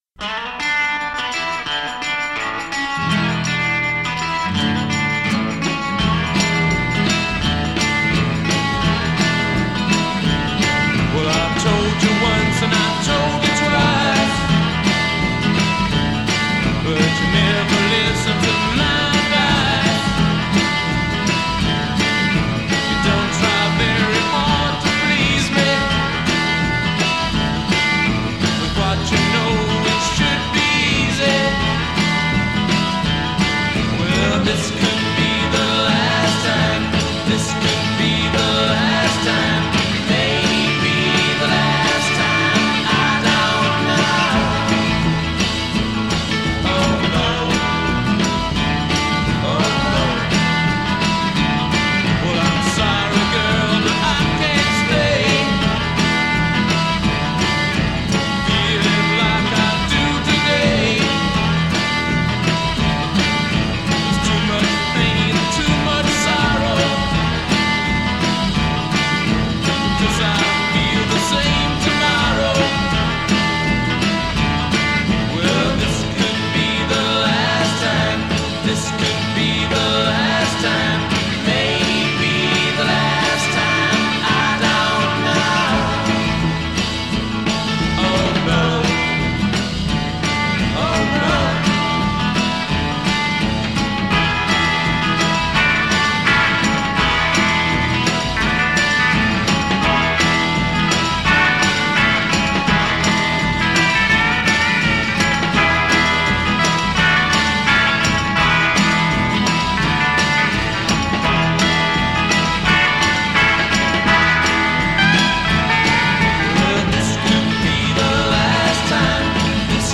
guitar solo
bass
drums
intro 0:00 8 guitar rif
A verse 0: 16 vocal solo with vocal response at tail a
refrain : 13 chorus & instrumental fill b
A verse : 16 guitar solo over verse accompaniment
coda : 16+ repeat hook from the refrain b